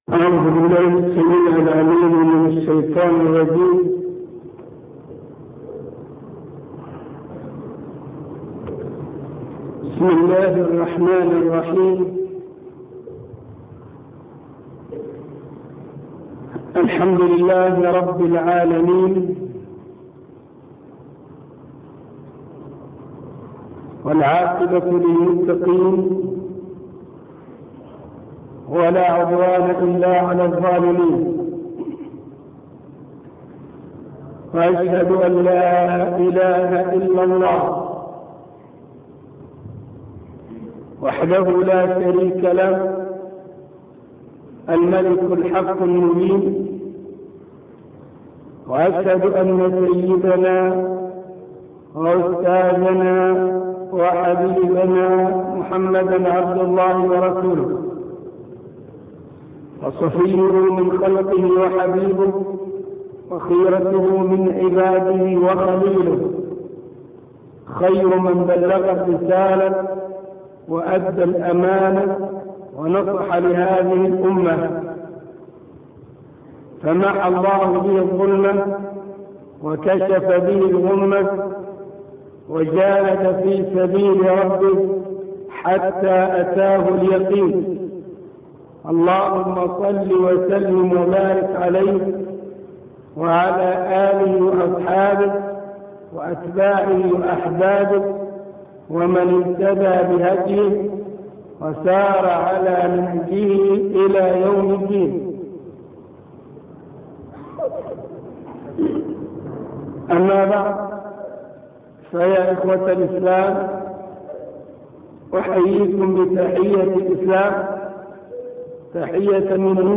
عنوان المادة الدرس(16) الأدلة على وجود الله -3- (التقدير والتسوية - شرح العقيده الإسلاميه تاريخ التحميل الأثنين 22 فبراير 2021 مـ حجم المادة 33.24 ميجا بايت عدد الزيارات 223 زيارة عدد مرات الحفظ 105 مرة إستماع المادة حفظ المادة اضف تعليقك أرسل لصديق